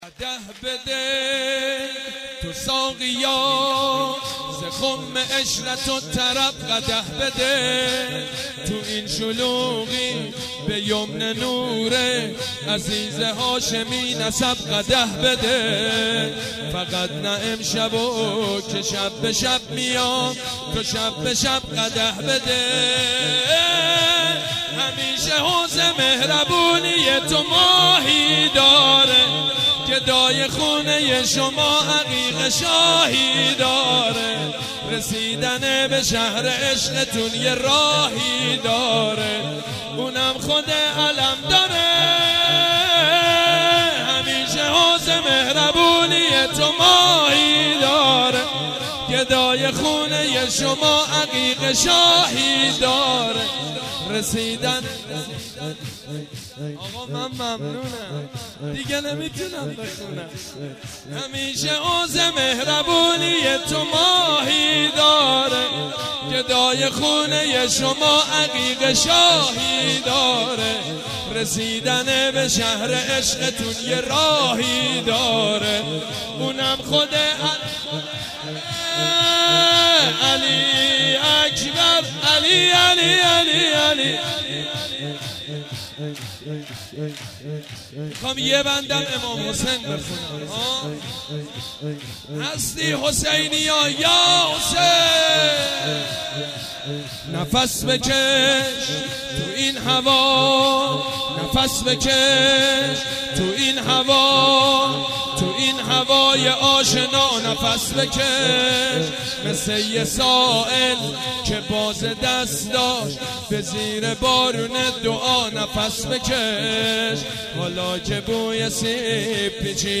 سرود: گدای خونه ی شما عقیق شاهی داره
سرود: گدای خونه ی شما عقیق شاهی داره خطیب: سید مجید بنی فاطمه مدت زمان: 00:06:50